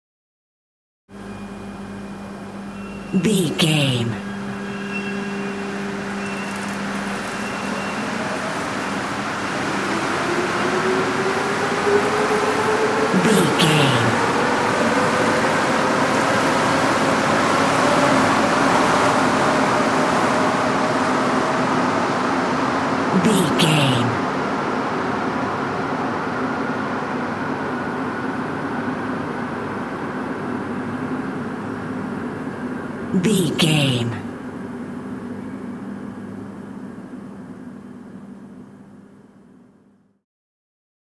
Subway train leave station
Sound Effects
urban
ambience